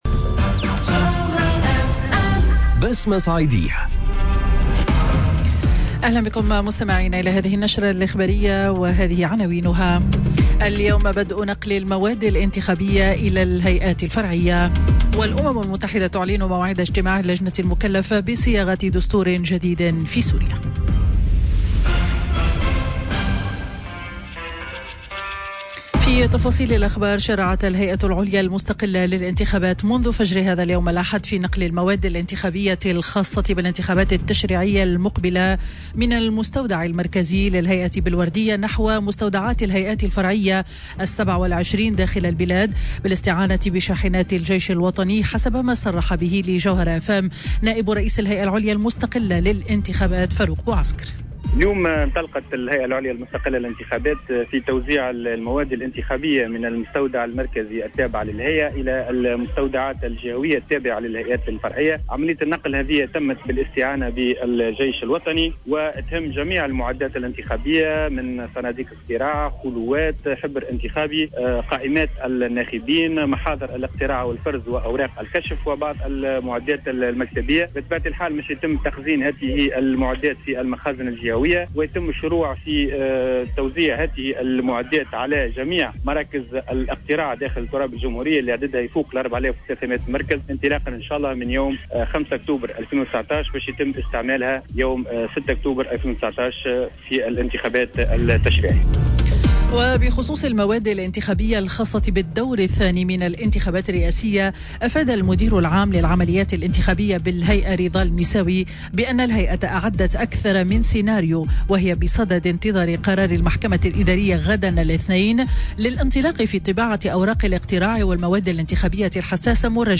Journal info 12h00 de dimanche 29 Septembre 2019